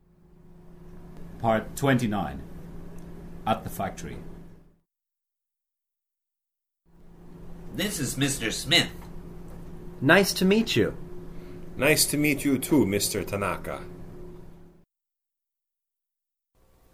A business English dialog series (with questions and answers for beginning level learners)